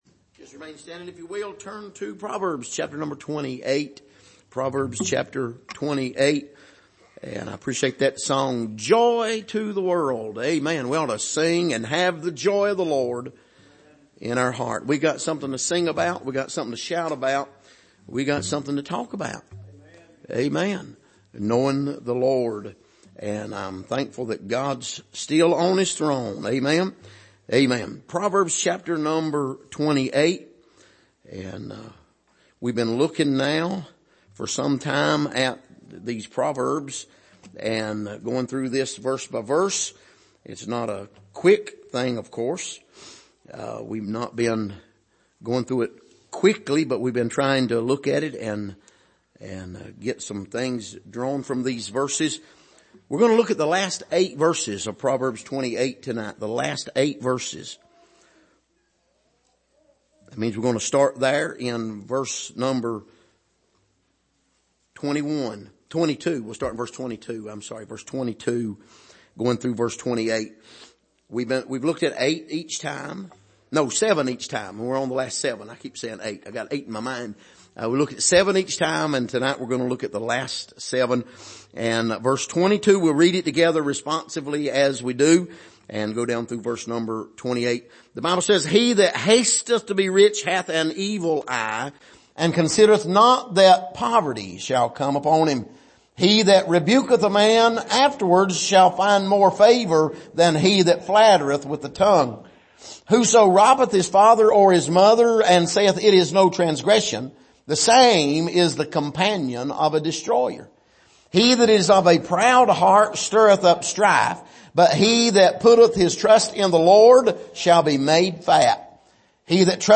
Passage: Proverbs 28:22-28 Service: Sunday Evening